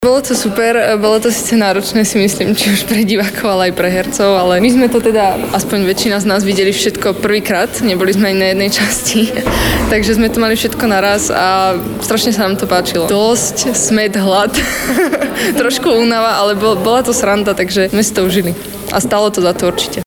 Divák-03.wav